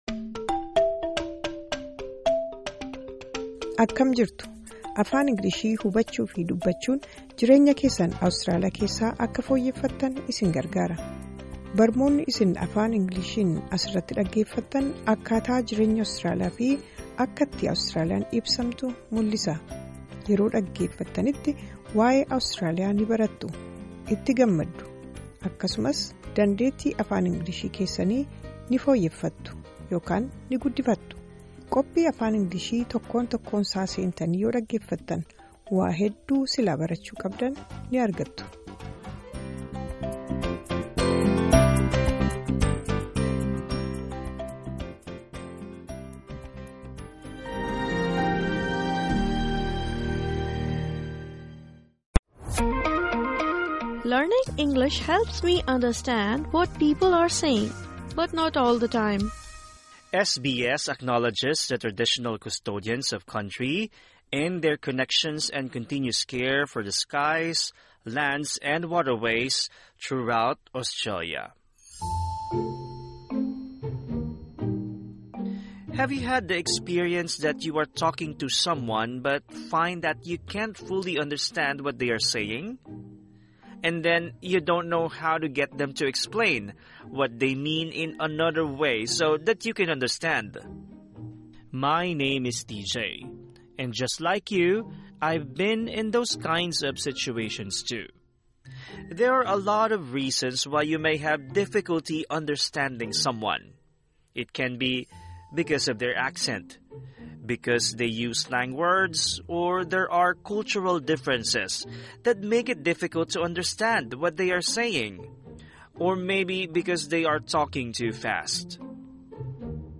Learn how to say you don’t understand. Plus hear a comedian’s funny story about misunderstanding an Australian idiom.